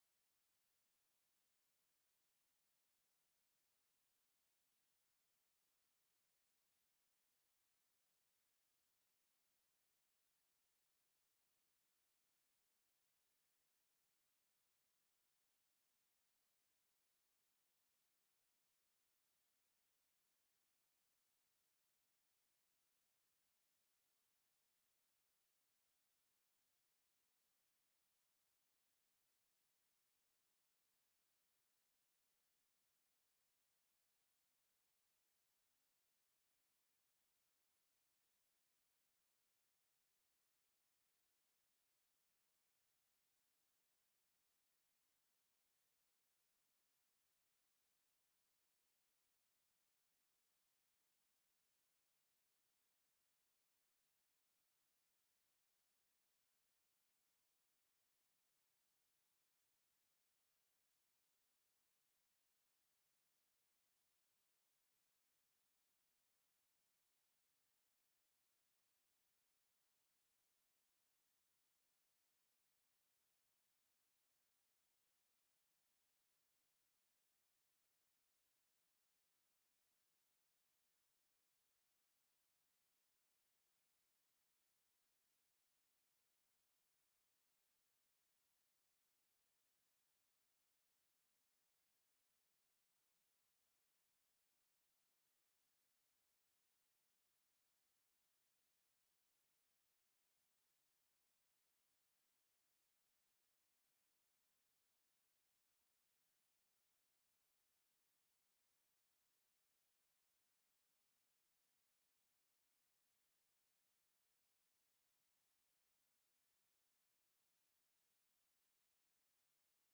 Praise and Worship at FWC on February 23 2025